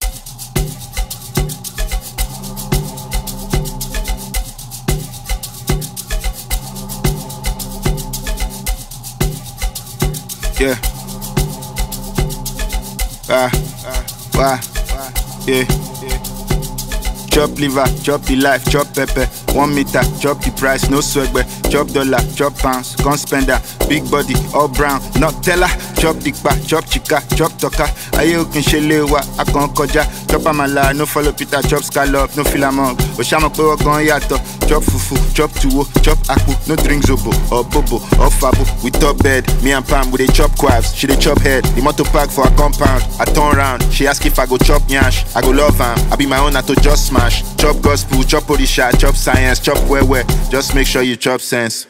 Nigerian rapper and songwriter